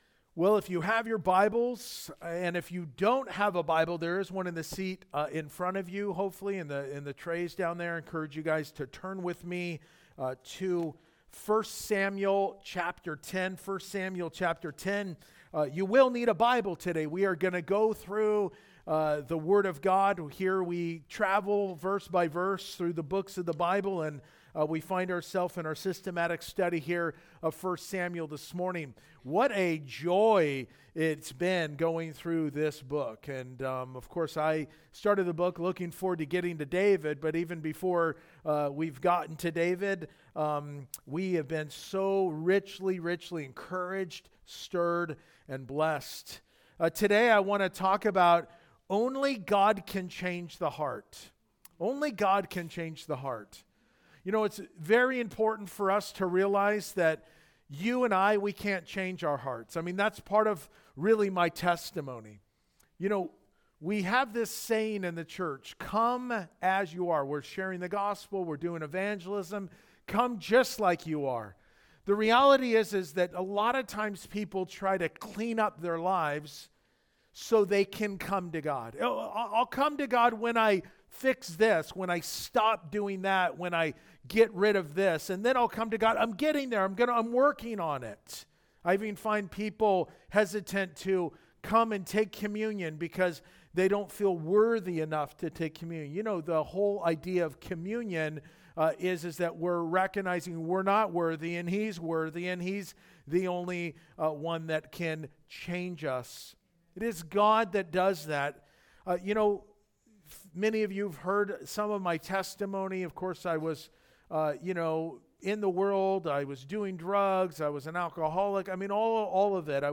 Only+God+Can+Change+the+Heart+2nd+Service.mp3